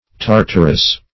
Tartarous \Tar"tar*ous\, a. [Cf. F. tartareux.]